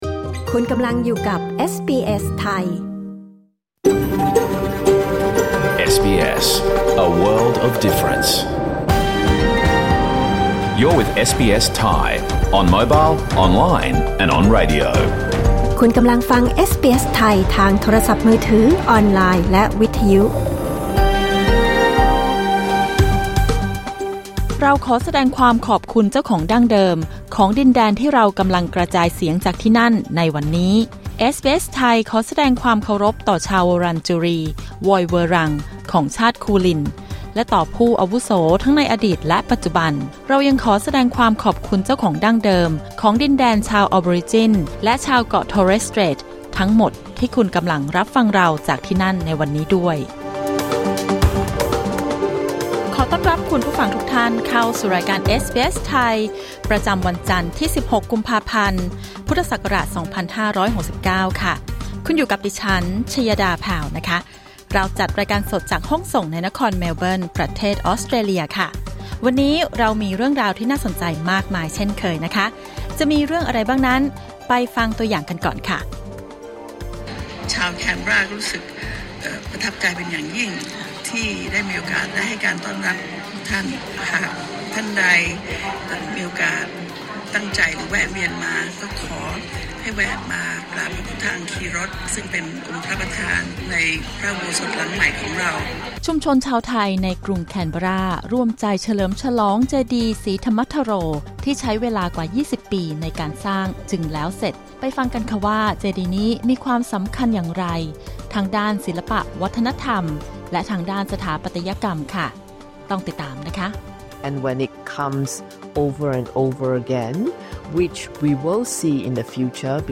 รายการสด 16 กุมภาพันธ์ 2569